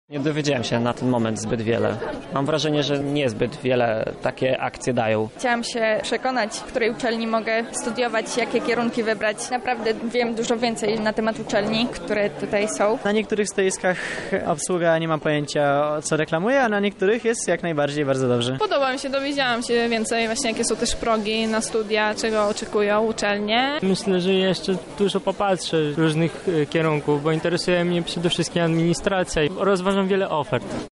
Salon Maturzystów – uczestnicy